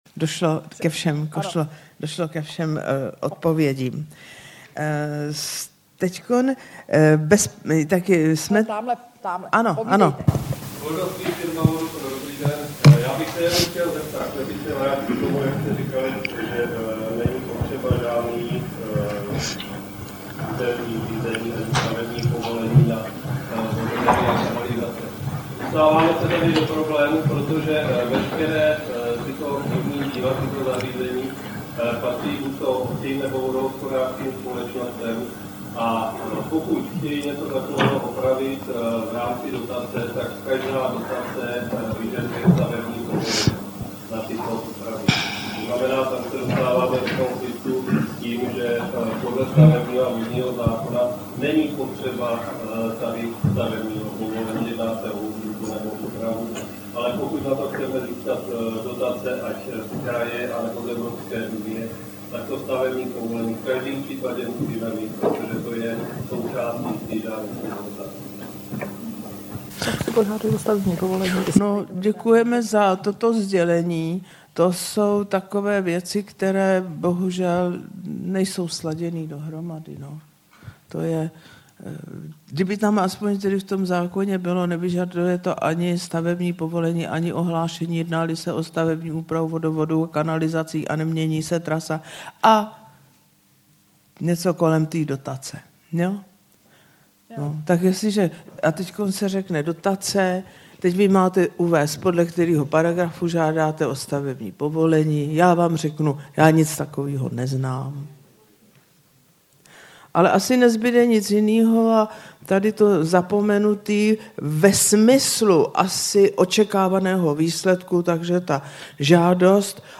Článek obsahuje zajímavosti a zároveň zvukový záznam z přednášek o provázání novely vodního zákona (zákon o vodách) a stavebního zákona.
Celé přednášky si poslechněte na zvukovém záznamu, který je s ohledem na rozdělení semináře rovněž ve dvou částech.